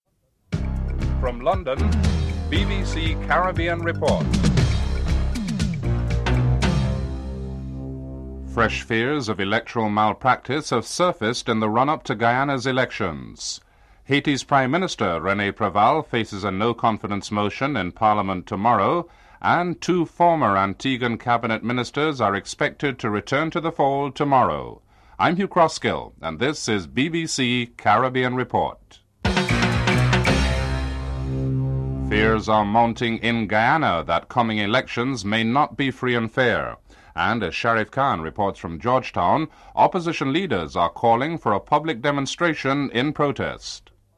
1. Headlines (00:00-00:31)
Comments from Dominica’s Prime Minister, Eugenia Charles and Dominica’s Minister of Foreign Affairs, Brian Alleyne (07:00-09:05)